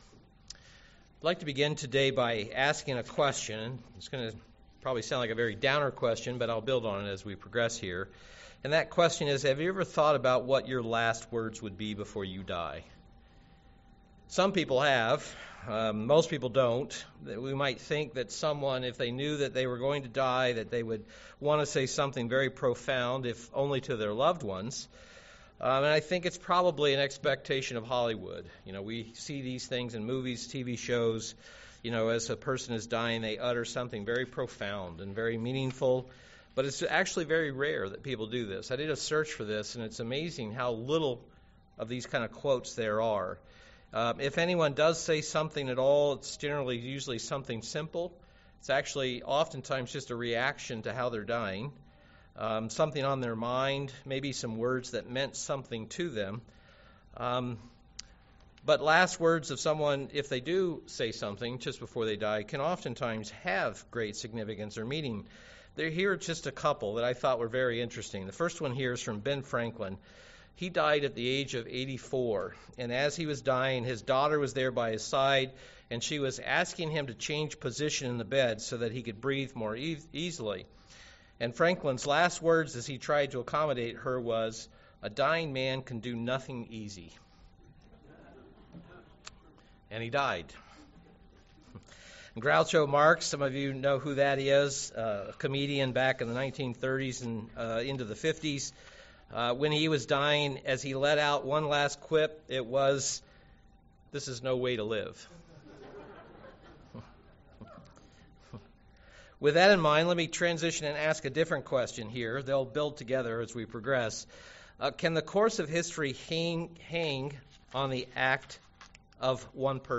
Given in Milwaukee, WI
UCG Sermon Christ sacrifice crucifixion learn thought insight Studying the bible?